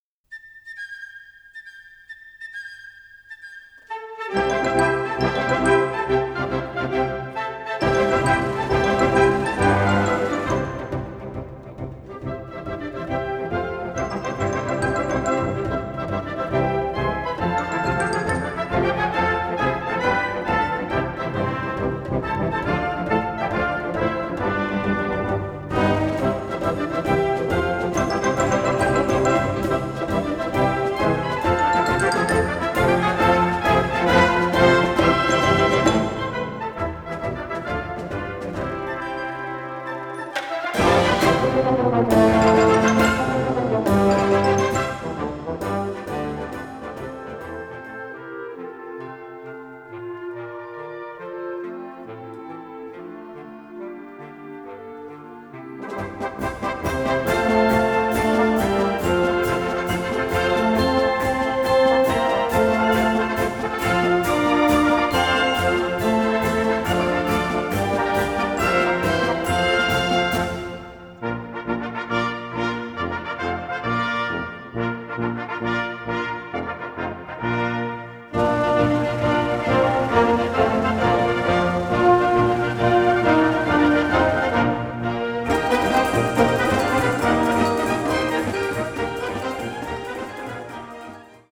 Concert March, 2'25", Gr. 3, WB FA